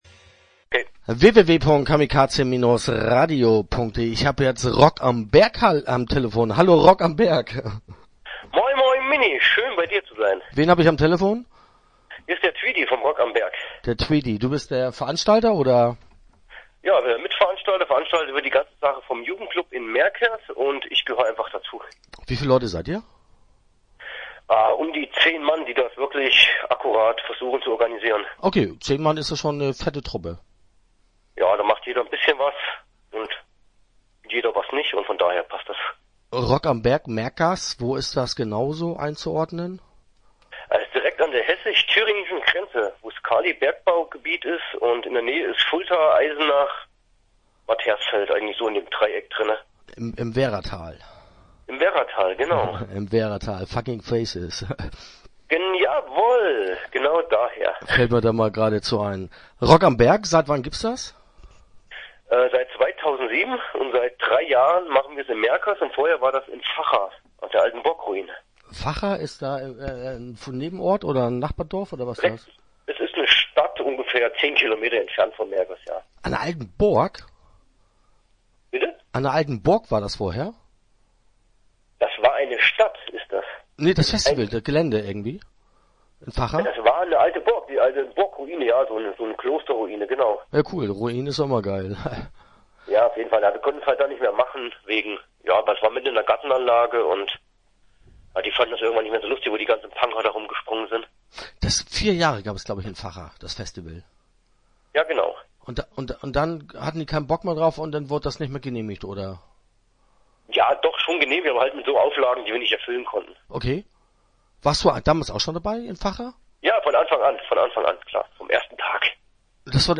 Start » Interviews » Rock am Berg